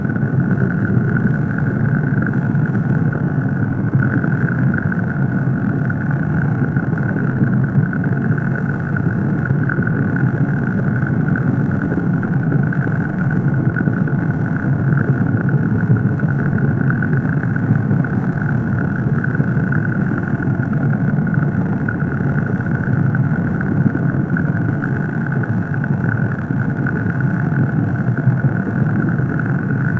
looping, ambience, lava area, lava bubbling, subtle
looping-ambience-lava-are-yrhwvo6z.wav